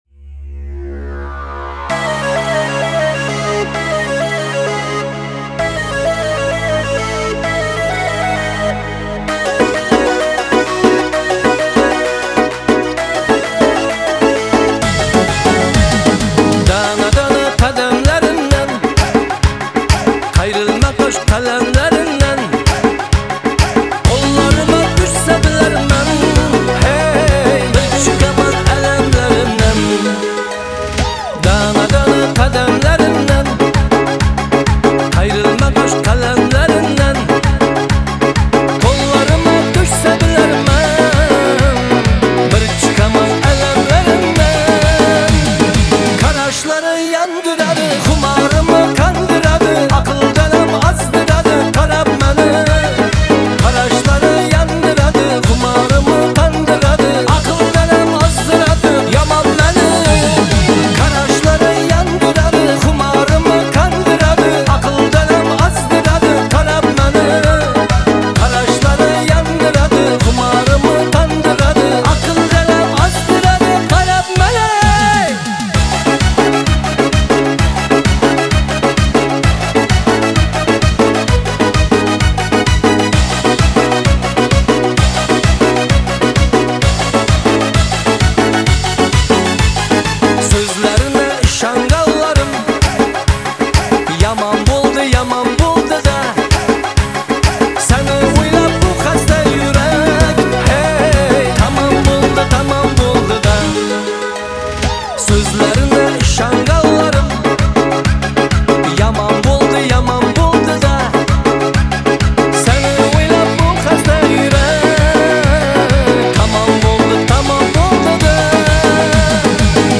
Трек размещён в разделе Узбекская музыка / Альтернатива.